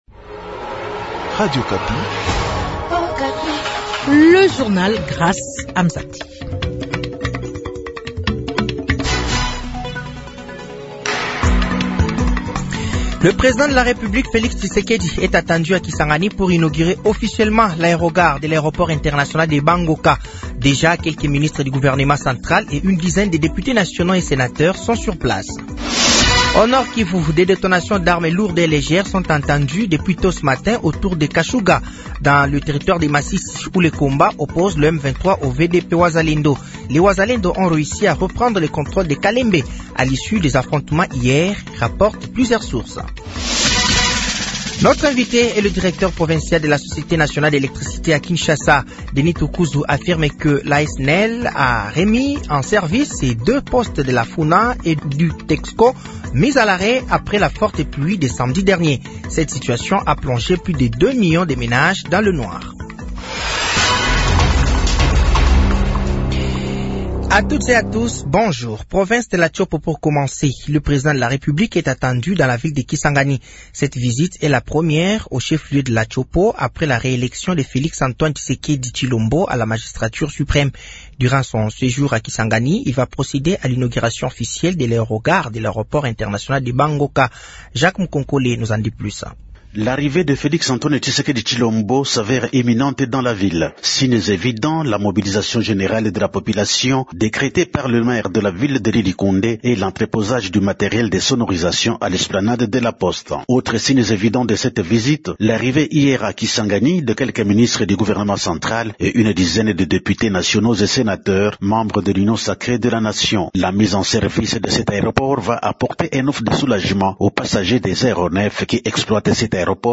Journal français de 15h de ce mardi 22 octobre 2024